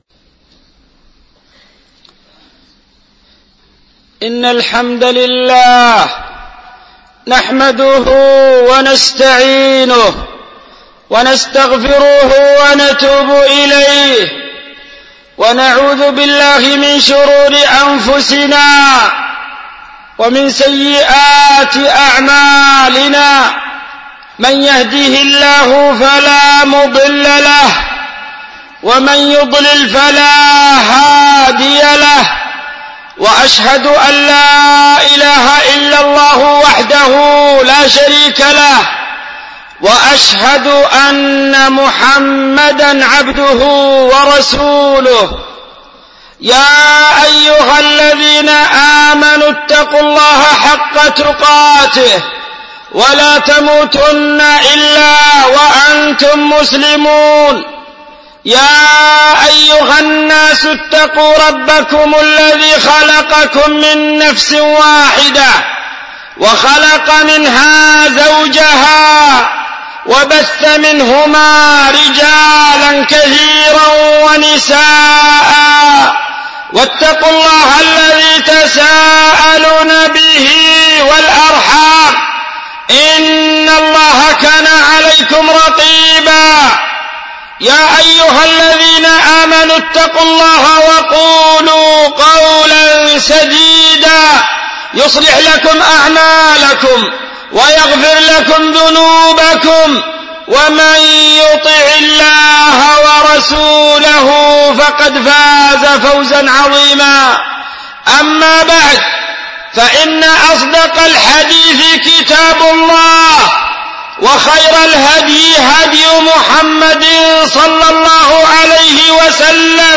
خطبة فعل خيرات في رمضان